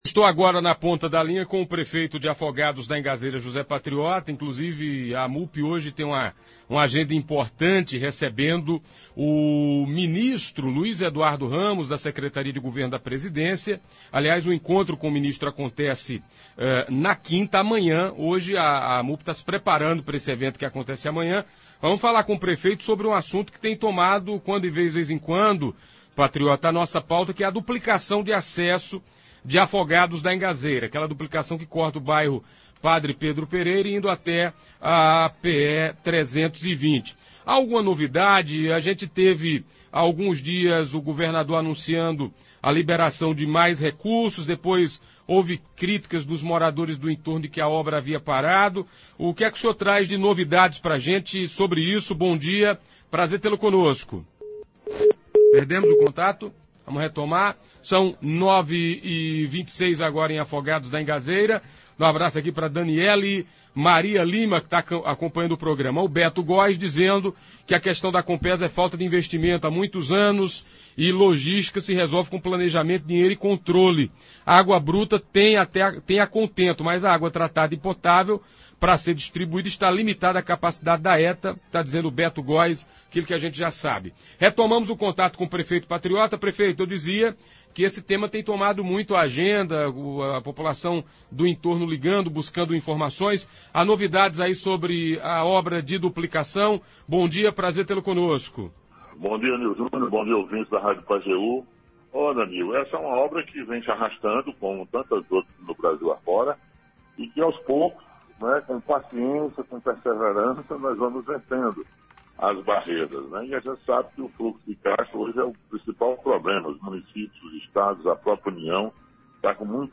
O prefeito de Afogados da Ingazeira, José Patriota, falou ao programa Manhã Total da Rádio Pajeú FM desta quarta-feira (2) sobre as obras de duplicação da rodovia que liga Afogados à PE-320. Ele também falou sobre obras travadas, como a do Pátio da Feira e obra no bairro Planalto.